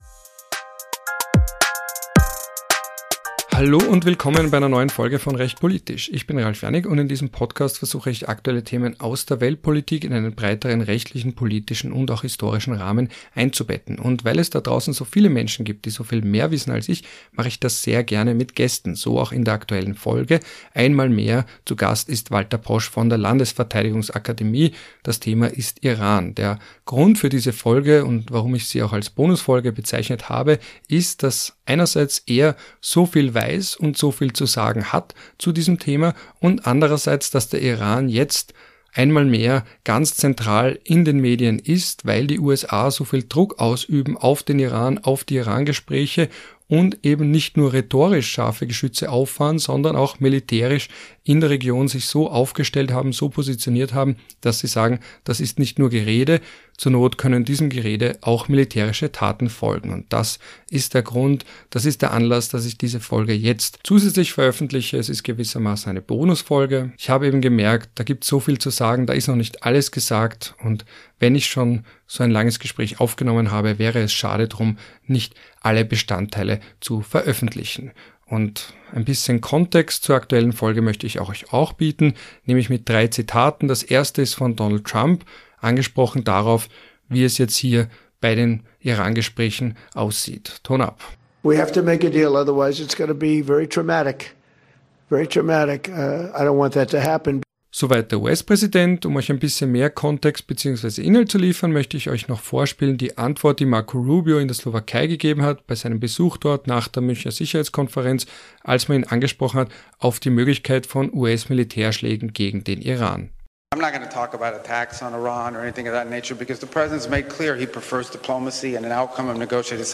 Teil zwei meines Gesprächs